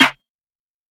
SNARE 14.wav